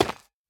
Minecraft Version Minecraft Version 25w18a Latest Release | Latest Snapshot 25w18a / assets / minecraft / sounds / block / ancient_debris / break4.ogg Compare With Compare With Latest Release | Latest Snapshot
break4.ogg